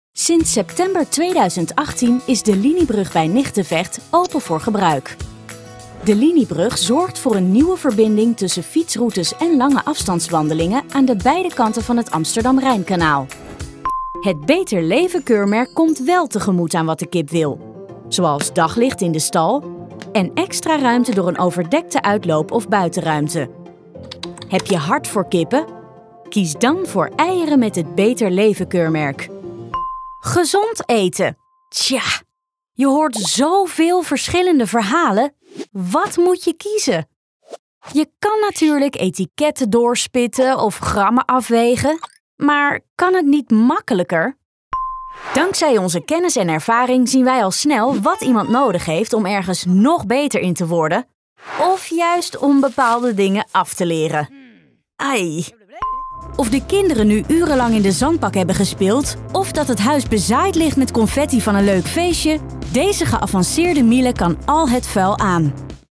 Explainer Videos
My voice sounds young, fresh and enthusiastic, but reliable.
Mic: Sennheiser MKH416